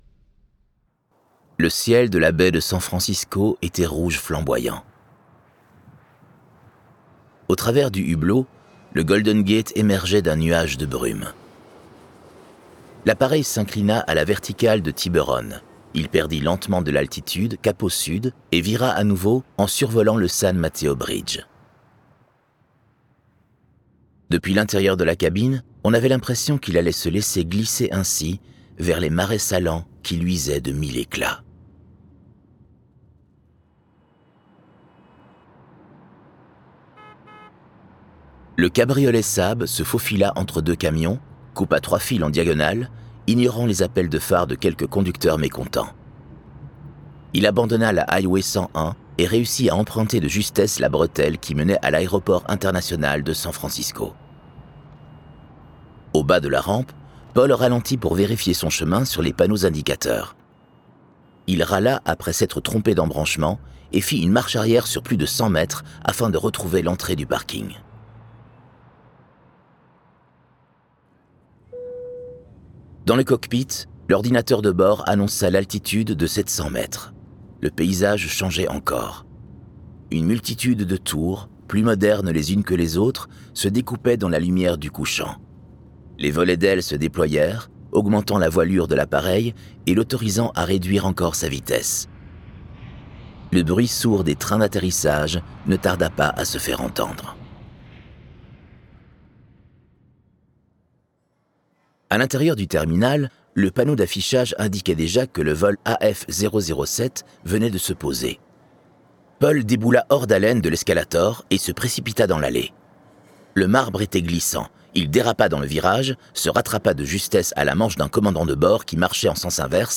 Je ne serai plus jamais seul puisque tu existes quelque part. Des comédien.nes distincts pour incarner chaque personnage, un accompagnement sonore